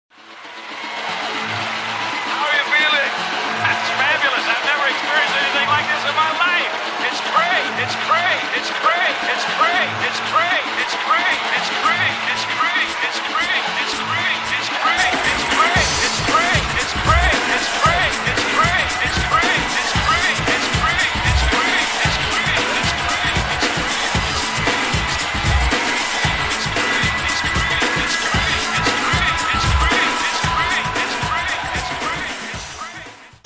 unique blend of trance, acid house, and fourth world samples